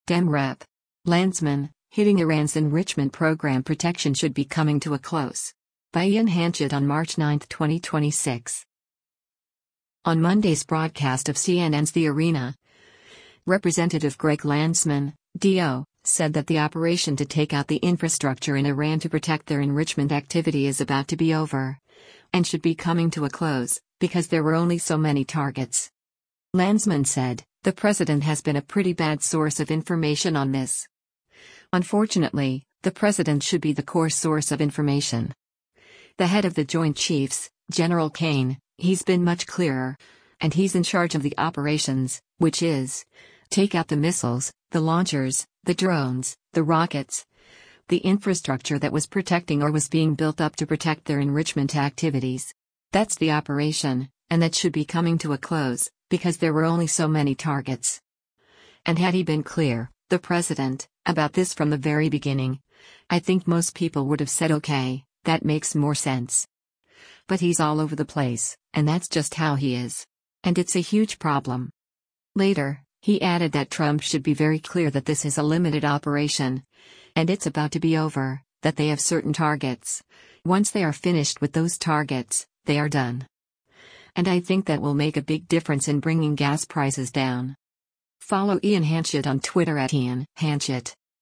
On Monday’s broadcast of CNN’s “The Arena,” Rep. Greg Landsman (D-OH) said that the operation to take out the infrastructure in Iran to protect their enrichment activity is “about to be over,” and “should be coming to a close, because there were only so many targets.”